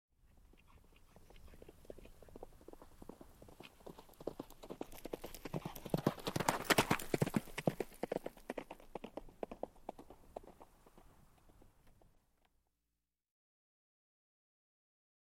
جلوه های صوتی
دانلود صدای اسب 48 از ساعد نیوز با لینک مستقیم و کیفیت بالا
برچسب: دانلود آهنگ های افکت صوتی انسان و موجودات زنده دانلود آلبوم انواع صدای شیهه اسب از افکت صوتی انسان و موجودات زنده